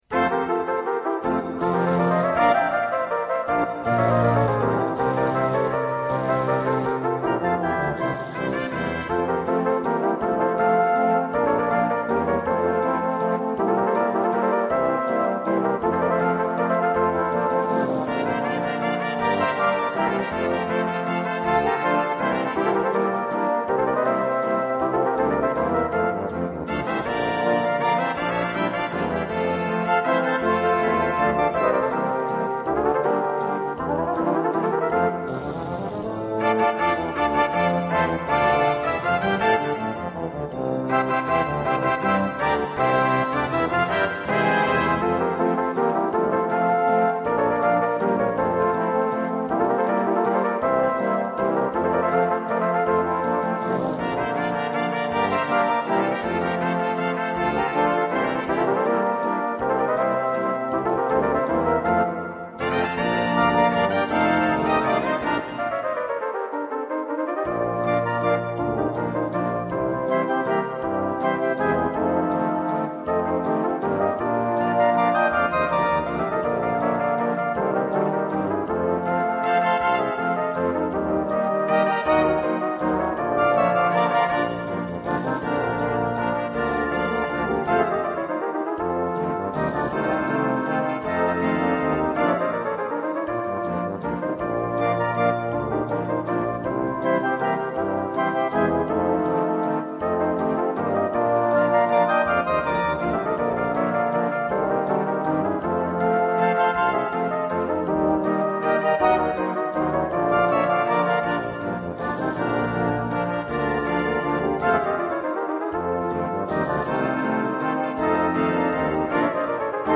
valse pour 2 bugles